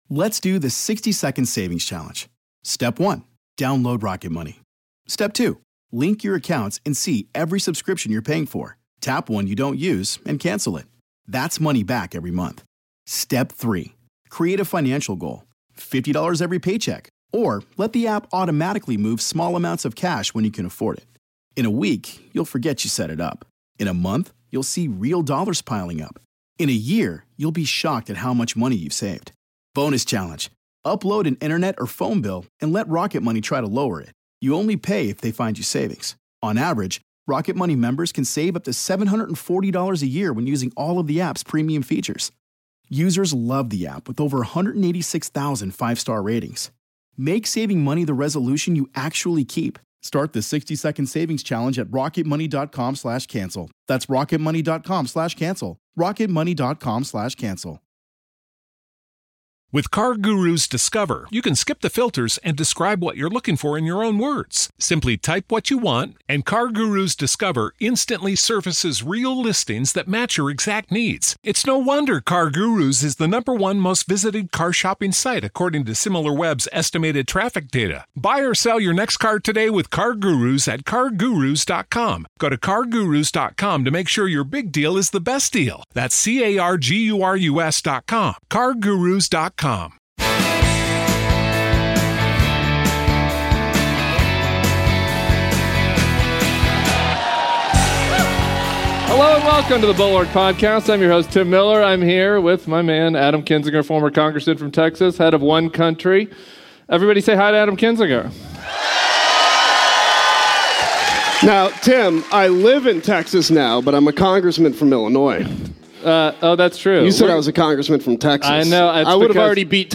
Live from Dallas
Before a packed house in D-Town, Tim Miller and Adam Kinzinger covered it all, from Trump's incoherent muttering about childcare to his graveside camera-mugging.
Plus big boos for Cruz, advice for Colin Allred, and should Kinzinger run for Congress from Texas? Our first of two live shows from the Lone Star State.